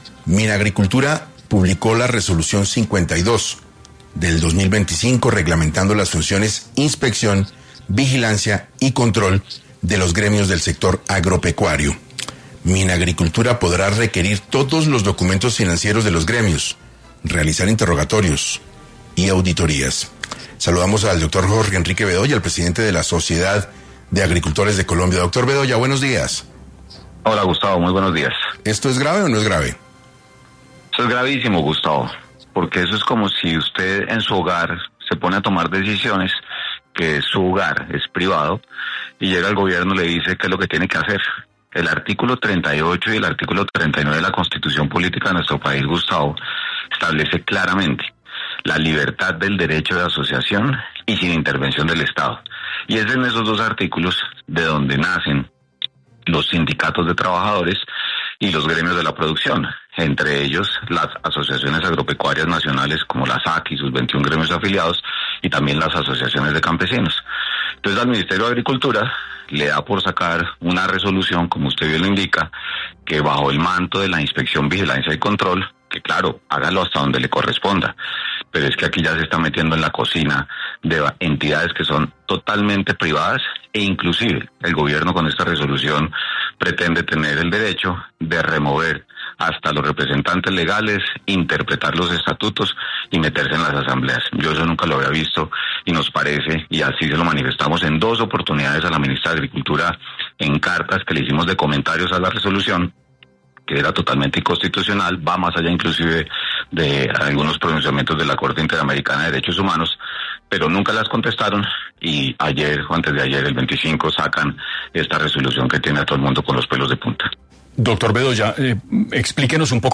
En entrevista para 6AM